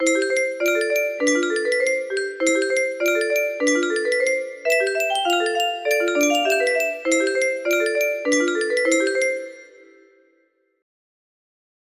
Grün, grün, grün Sind alle meine kleider music box melody